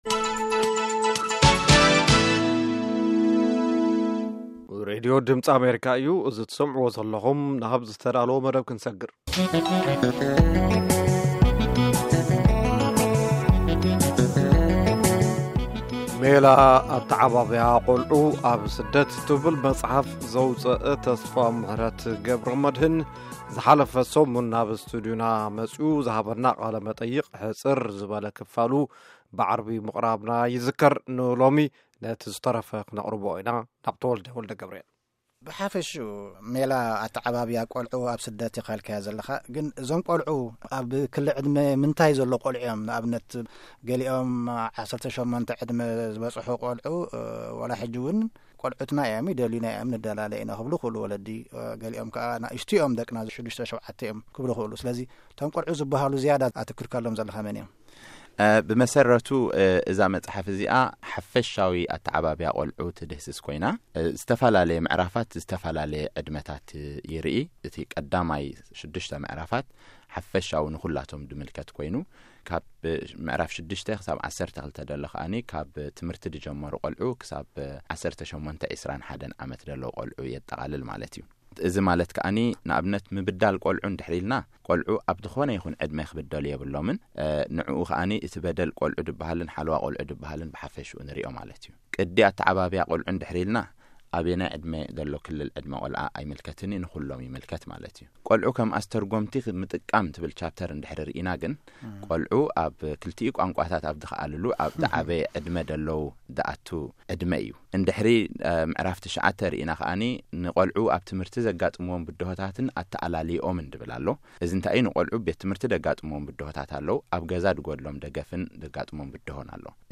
ካልኣይ ክፋል ቃለ-መጠይቕ